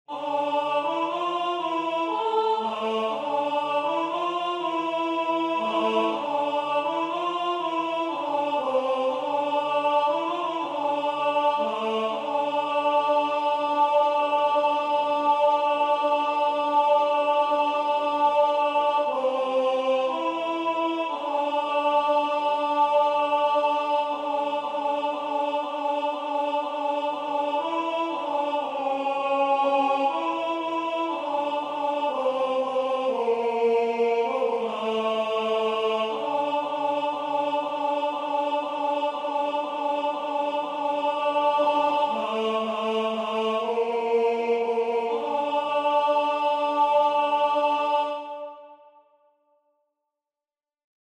version voix synth.